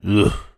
Звуки похмелья
Звук тяжёлого вздоха при похмелье